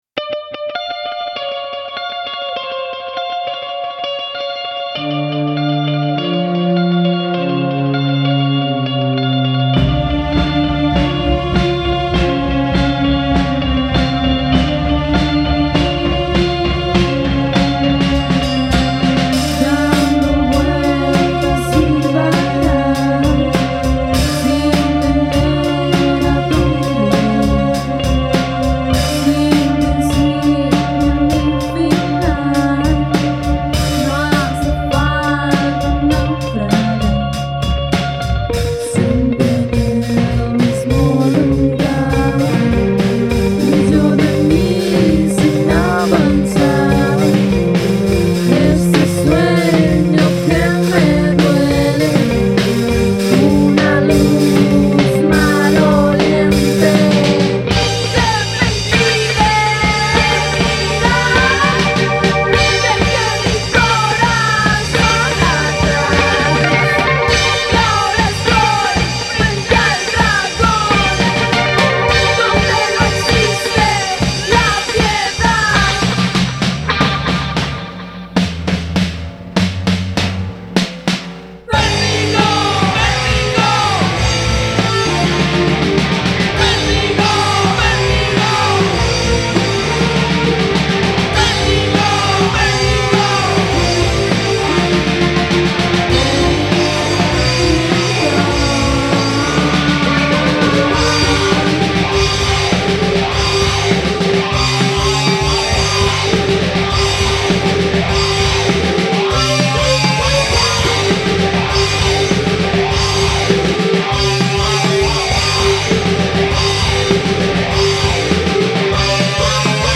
rock mestizo y paralelo
Rock Alternativo